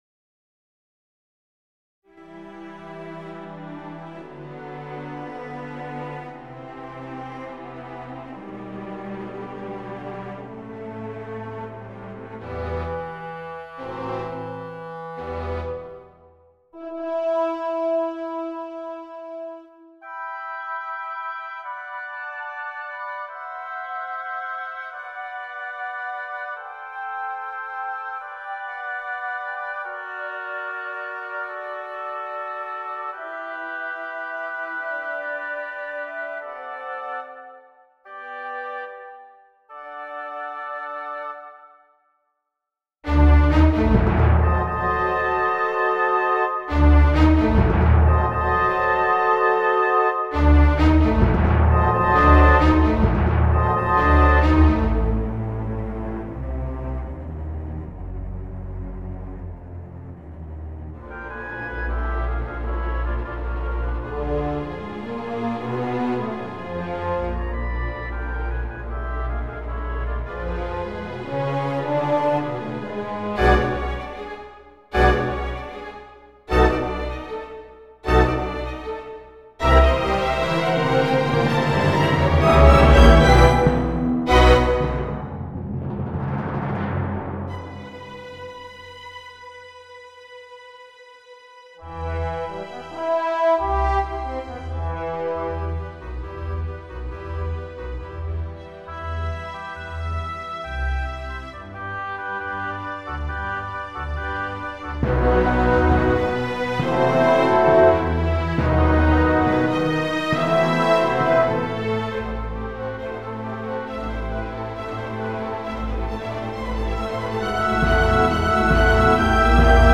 Démo de l’orchestre